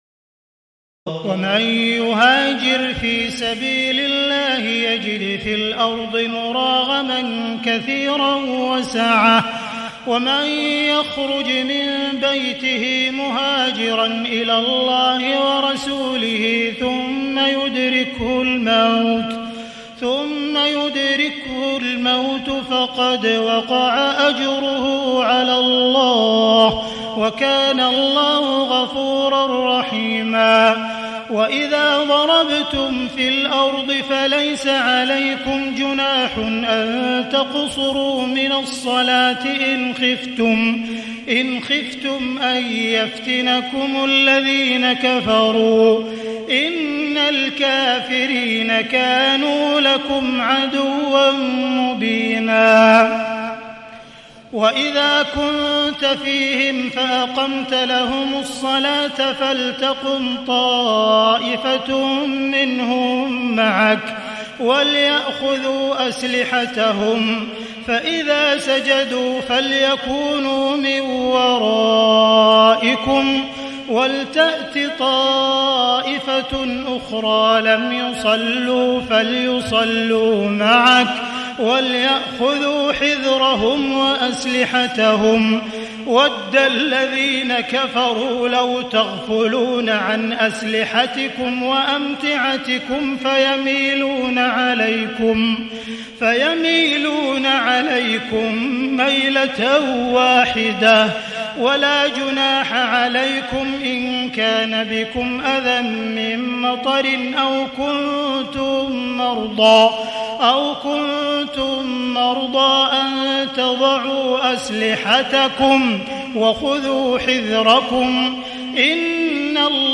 تهجد ليلة 25 رمضان 1437هـ من سورة النساء (100-147) Tahajjud 25 st night Ramadan 1437H from Surah An-Nisaa > تراويح الحرم المكي عام 1437 🕋 > التراويح - تلاوات الحرمين